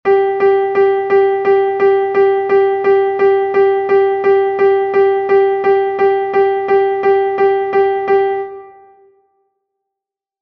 vivace.mp3